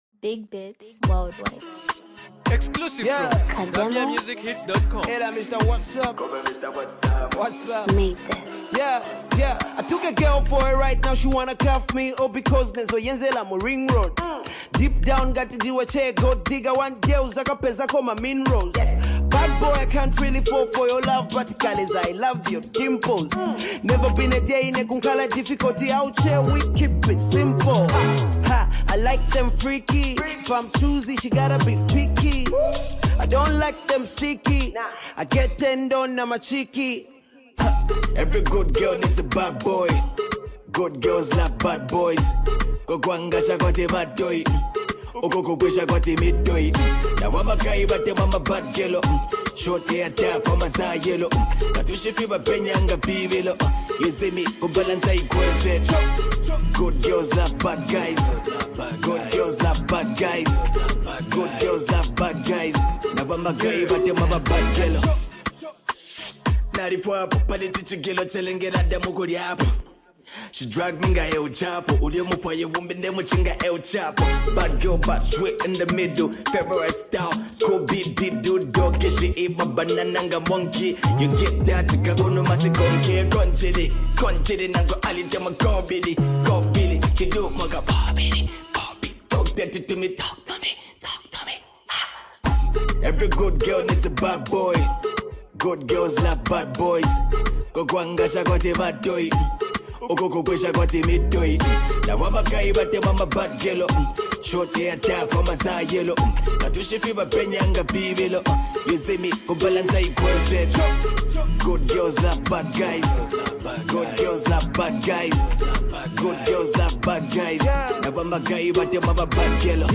dancehall melody